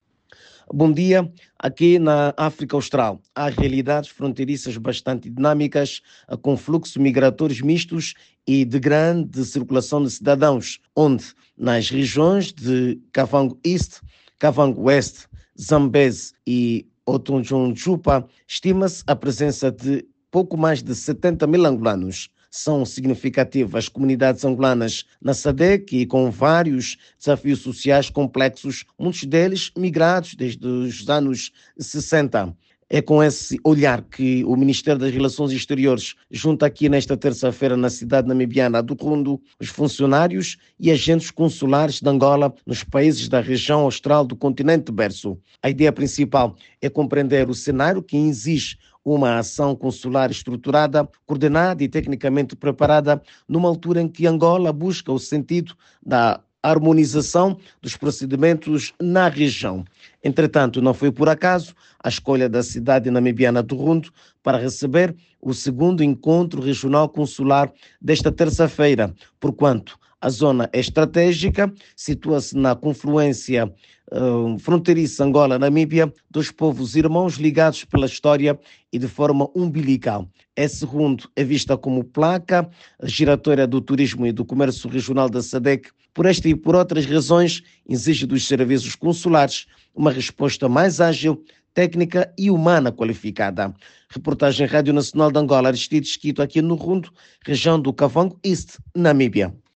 É o segundo encontro regional e está a ser promovido pelo Ministério das Relações Exteriores. A partir de Rundu, a reportagem